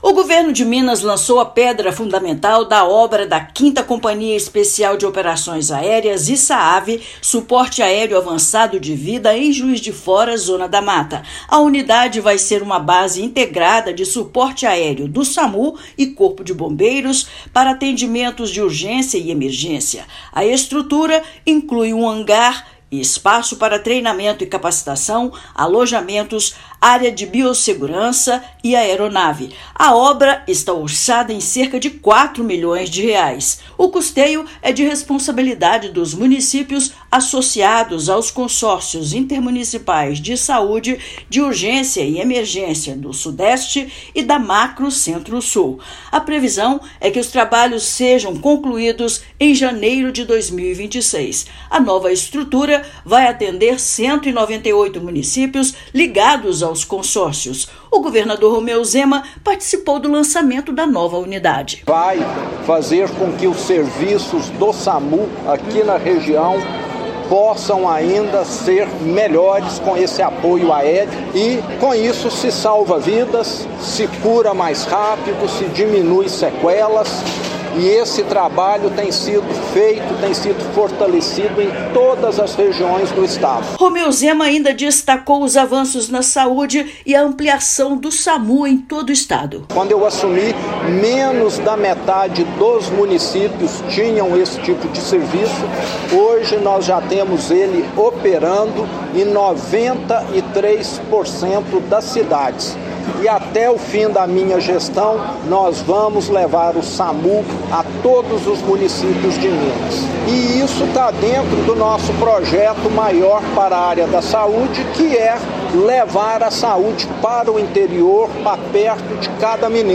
Estrutura faz parte da ampliação do Suporte Aéreo Avançado de Vida (SAAV), maior prestador de serviço aeromédico do SUS no Brasil. Ouça matéria de rádio.